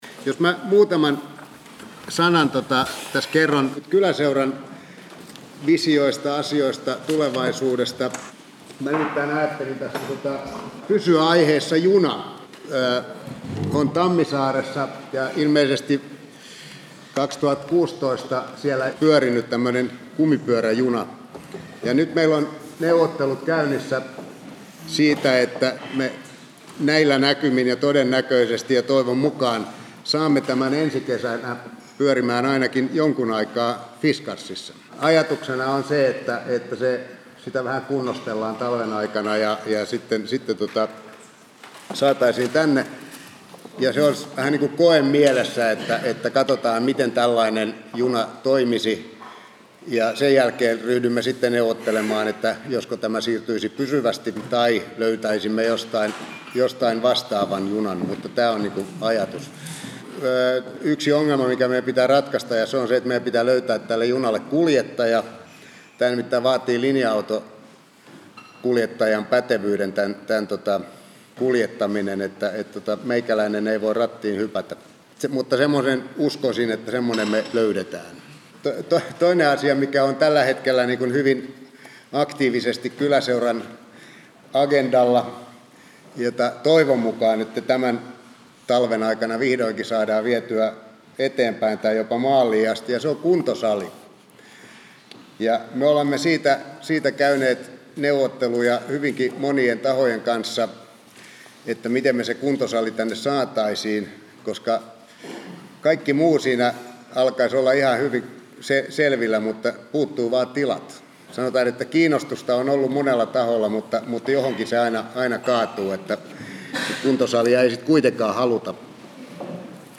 Kooste Fiskarsin Lukaalilla käydystä kyläkeskustelusta. Mitä Fiskarsissa juuri nyt tapahtuu? Miksi asioiden pitää muuttua ja mihin muutokset johtavat?